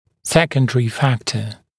[‘sekəndərɪ ‘fæktə][‘сэкэндэри ‘фэктэ]вторичный фактор, второстепенный фактор